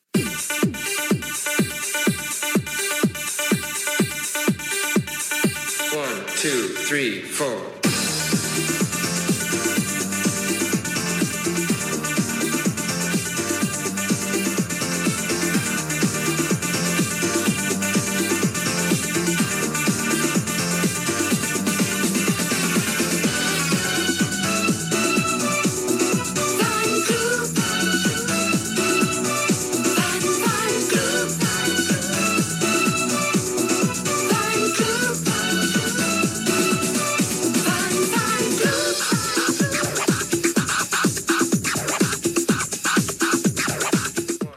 Sintonia del programa
Musical